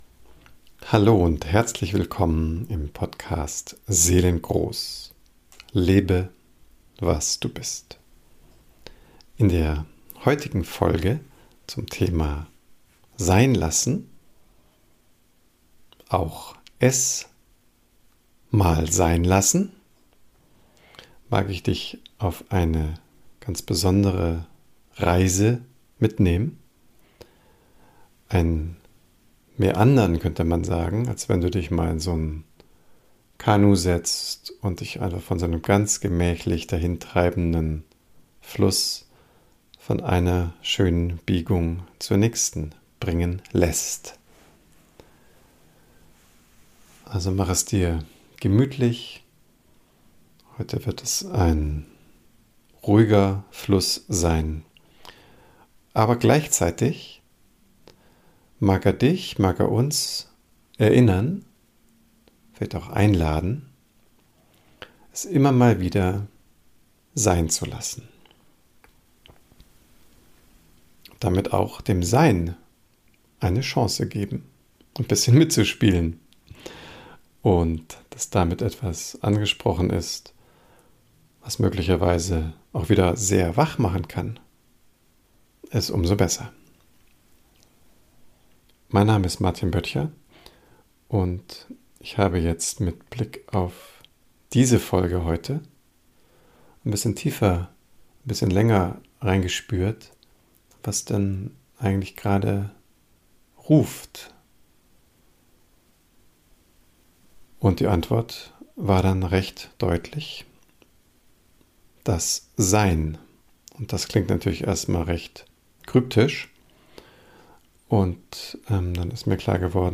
In dieser Folge geht es etwas geruhsam zu.